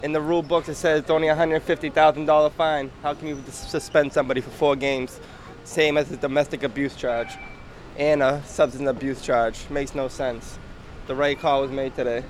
MAN AT SULLIVAN STATION T SAYS THE RIGHT CALL WAS MADE TODAY BY THE JUDGE